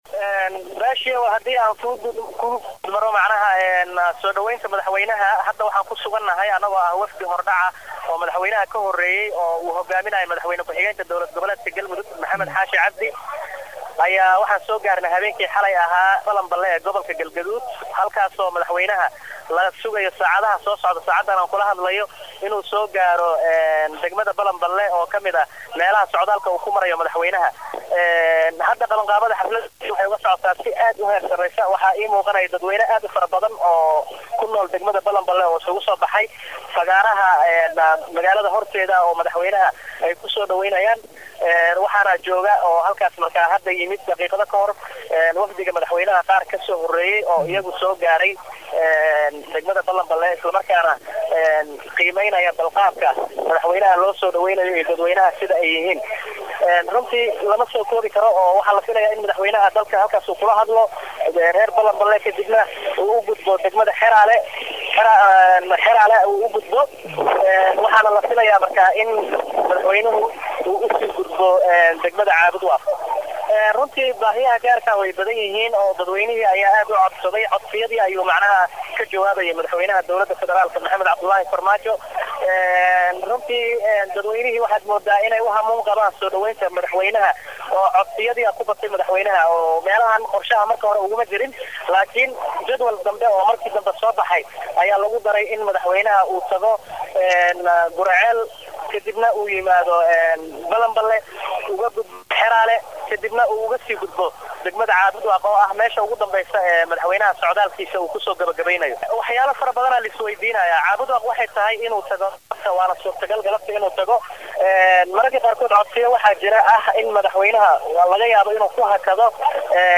Halkaan hoose ka Dhageyso Codka Wasiir ku Xigeenka Warfaafinta Galmudug
Wareysi-wasiir-heeloos-.mp3-hhhhhhhhhhhhhhhhhhhhhhhhhh.mp3